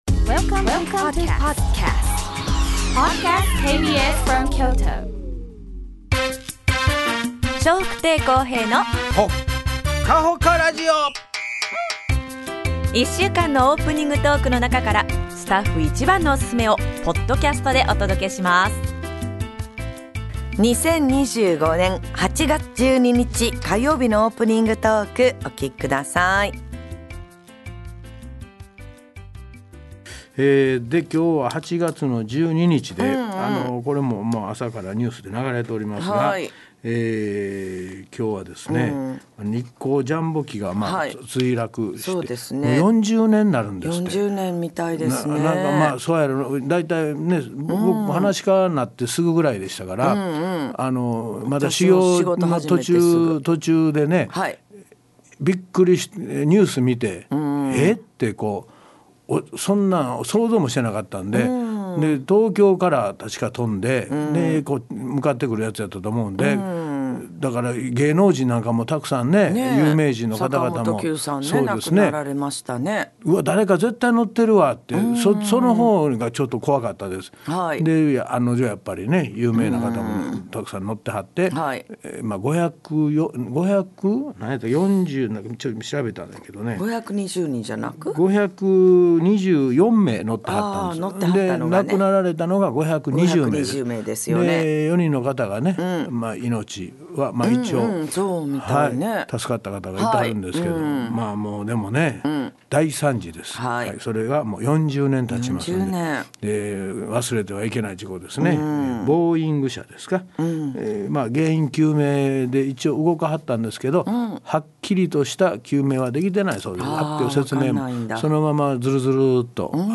2025年8月12日のオープニングトーク